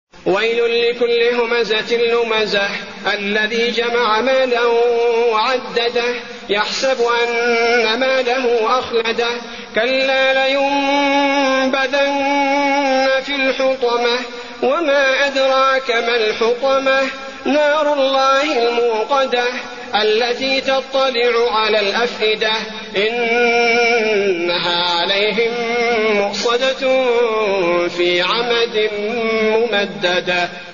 المكان: المسجد النبوي الهمزة